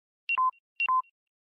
Разряженный аккумулятор на Android 5.0